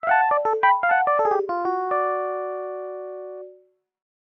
알림음 8_Notice2.mp3